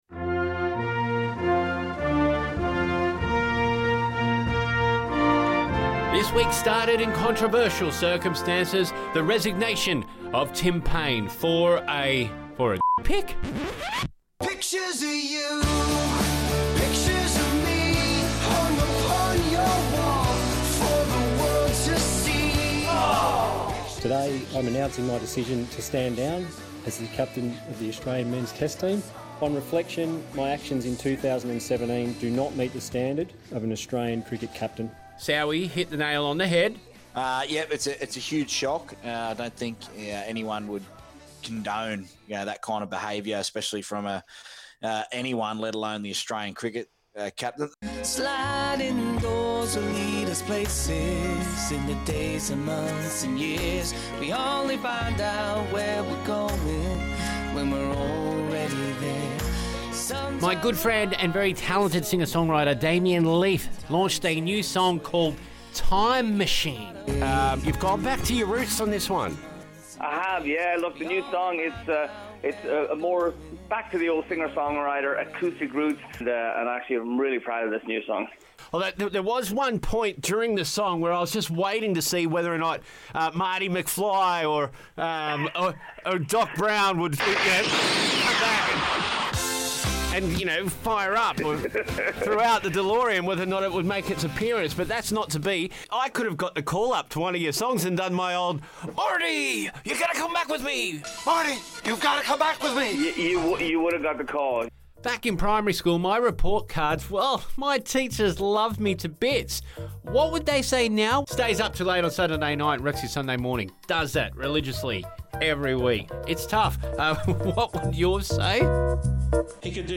Check out some of the fun from this weeks 2ST Brekky Show